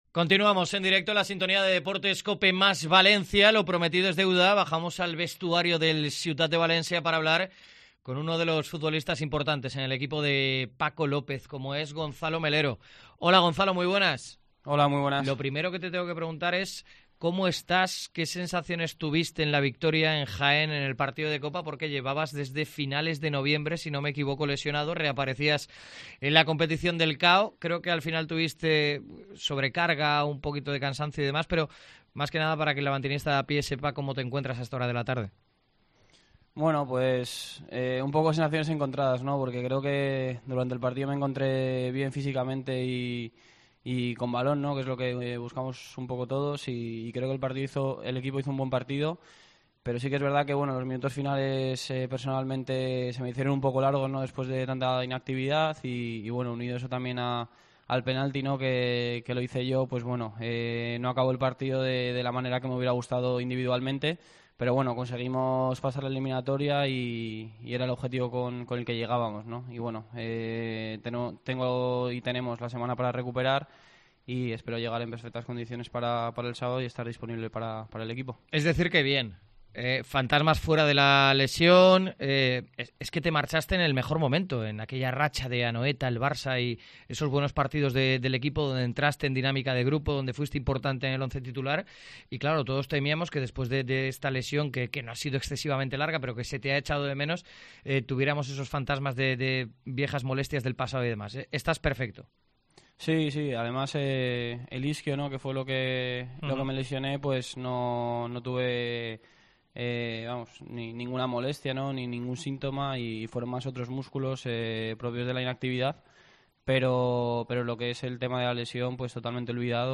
ENTREVISTA A GONZALO MELERO EN COPE